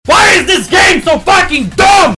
FailSound1.wav